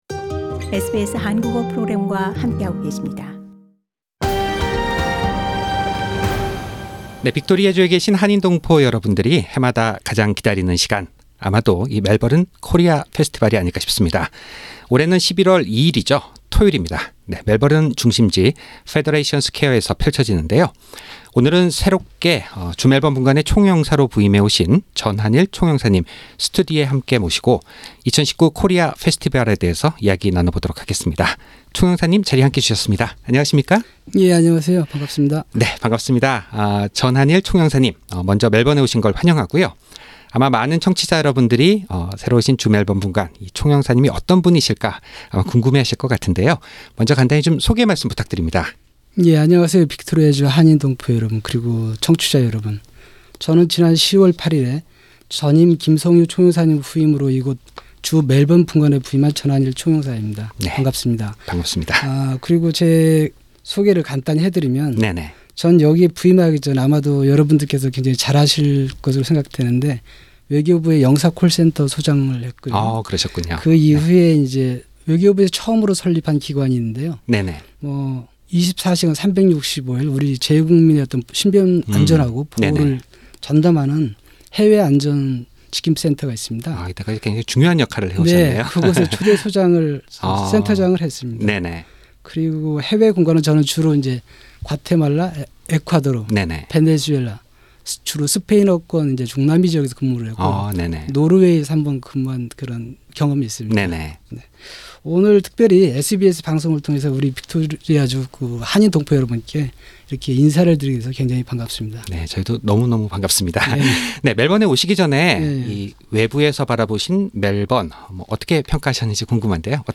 Korean Consul-General Mr Hanil CHEON has joined the interview and he was expecting a lot of audiences and Korean Community in Victoria to join the 2019 Korea Festival which will be held on Saturday, 2 November 2019.